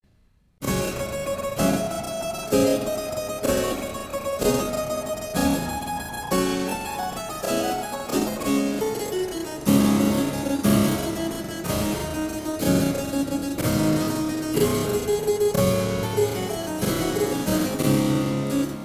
Soit, en Ré mineur : Gm = IVm
K 141 Ouverture (ralentie)
Dm(sus#4 // Dm(sus#4) // A // Gm(sus#4)/Bb // A(sus4) // Dm/F //
Gm // Dm/A + A7 // Dm ||